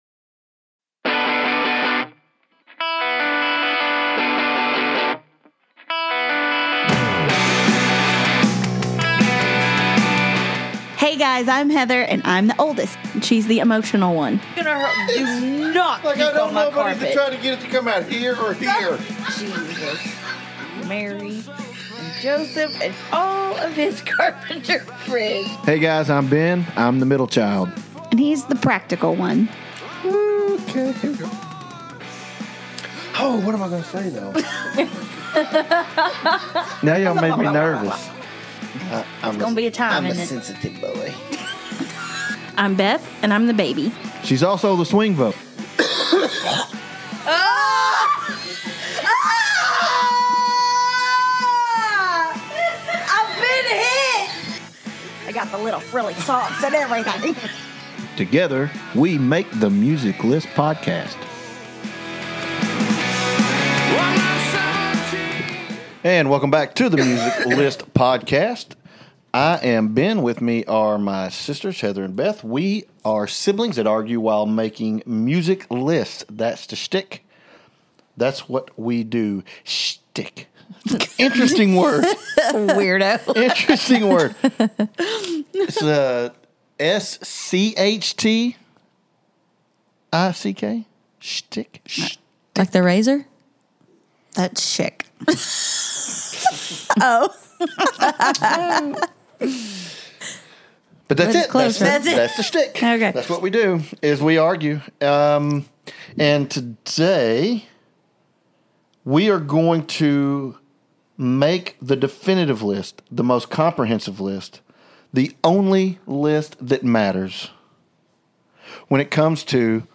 The Music List Podcast takes a recipe for entertainment- a hotly debated musical topic, three siblings who love to argue, and open mics, and then tosses them into a mixing bowl.